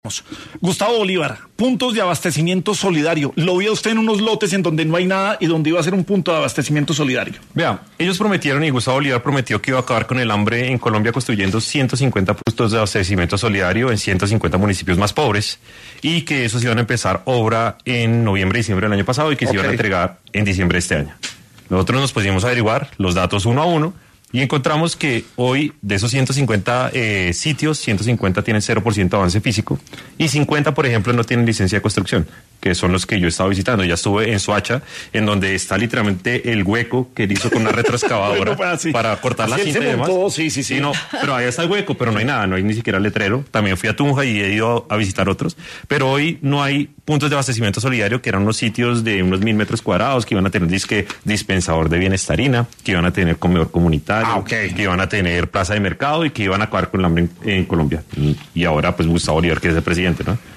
En su paso por Sin Anestesia de Caracol Radio, estuvo el concejal, Daniel Briceño, y habló sobre las promesas fallidas de Gustavo Bolívar para combatir el hambre